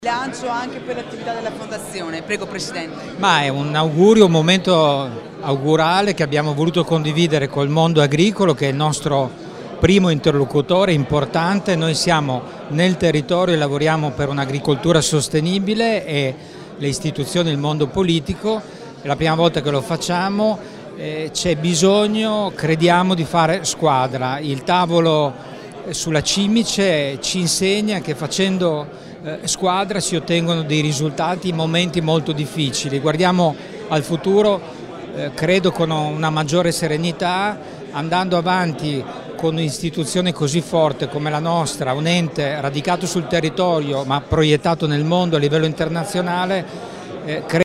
Scambio di auguri natalizi, nel tardo pomeriggio di ieri, nella sala del capitolo dell’ex monastero agostiniano di San Michele tra la Fondazione Edmund Mach e i rappresentanti del mondo agricolo, alla presenza del presidente della Provincia autonoma di Trento, Maurizio Fugatti, intervenuto con l’assessore provinciale all’agricoltura, Giulia Zanotelli e l’assessore provinciale allo sviluppo economico, ricerca e lavoro, Achille Spinelli.